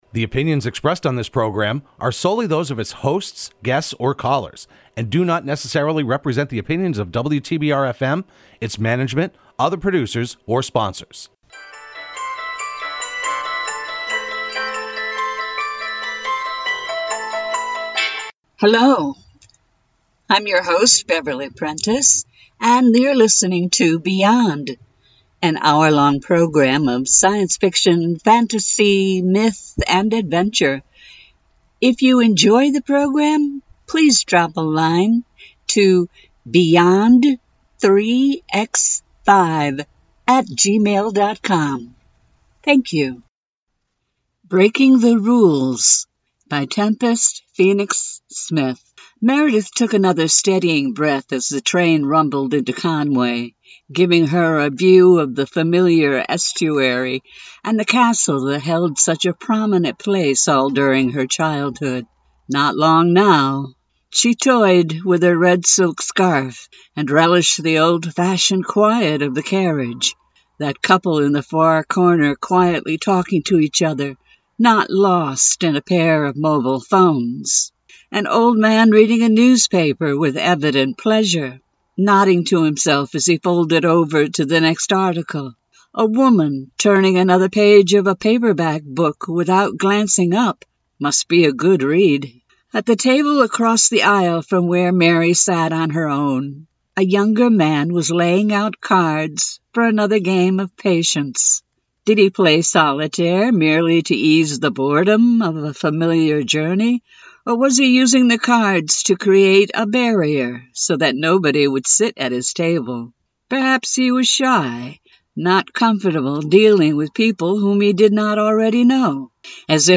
reads some short stories